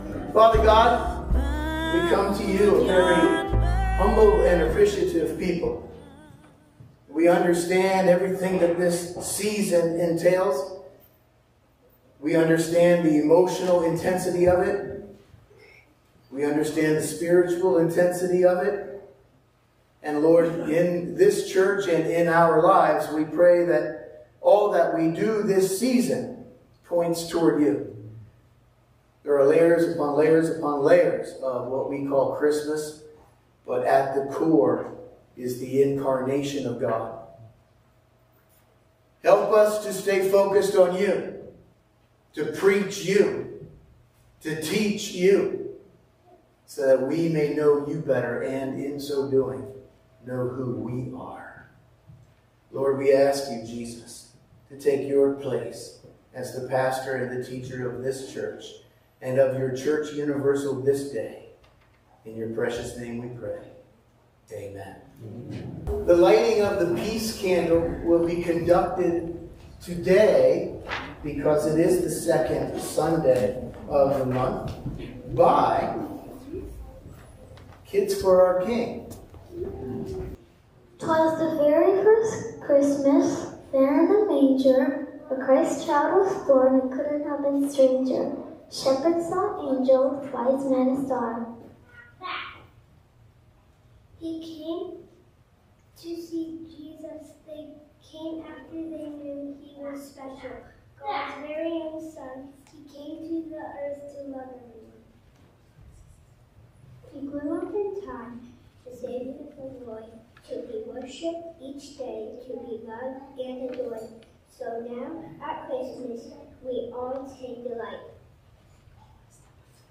Sunday Morning Service – December 8, 2024
Join us for the Sunday morning service at the Churchtown Church of God.